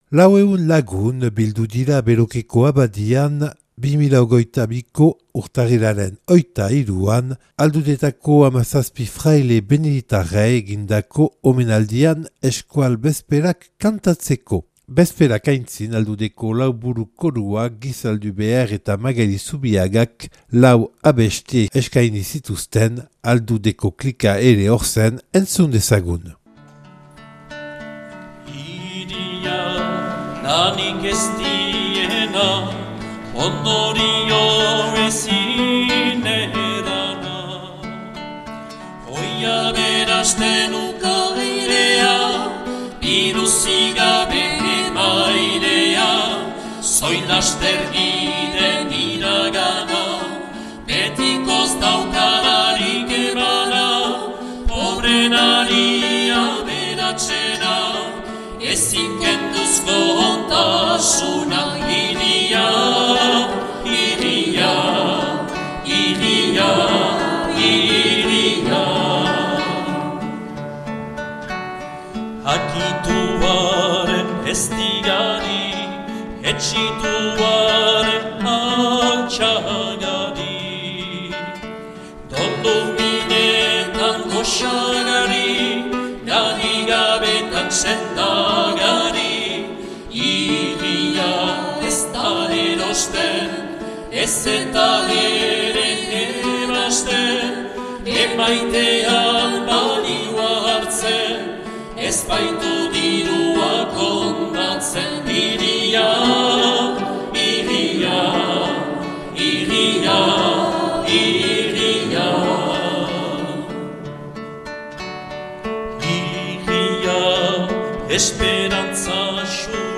Aldudetako 17 fraile beneditarrei egindako omenaldia 2022. urtarrilaren 23an Belokeko frailetxean
Omenaldia eta Bezperak euskaraz.